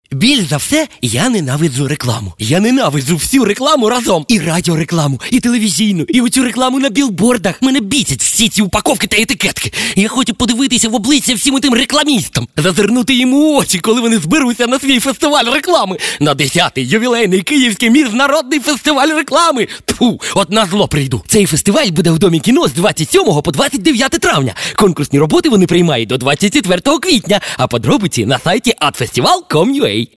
Речь, естественно, о рекламном радиоролике 10-го Киевского Международного Фестиваля Рекламы, краткую историю создания которого рассказывают его создатели:
Краткая история написания простого, но гениального ролика для 10-го юбилейного КМФР.